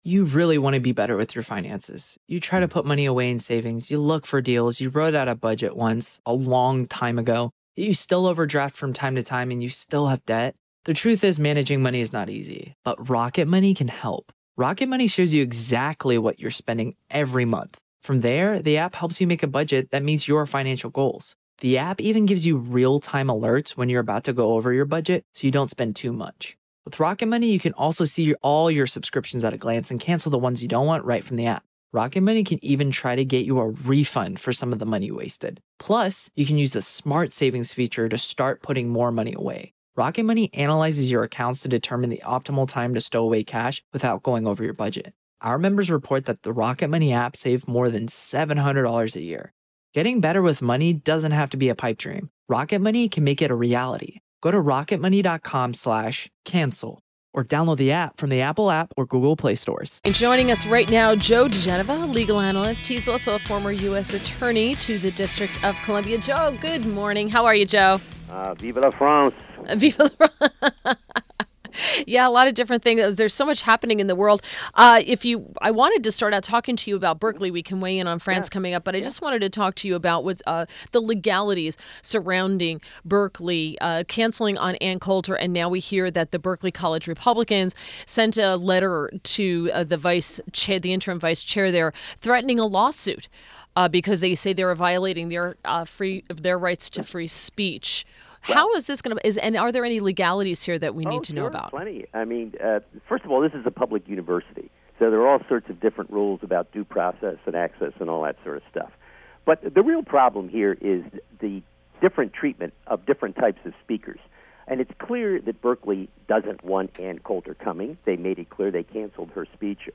INTERVIEW — JOE DIGENOVA – legal analyst and former U.S. Attorney to the District of Columbia